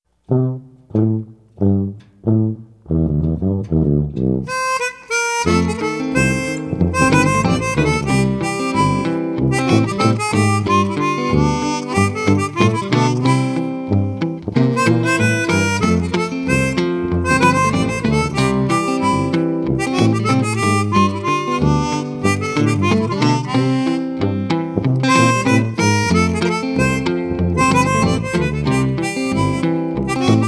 Jazz and blues from the Crescent City
Traditional Jazz Combo, 11 songs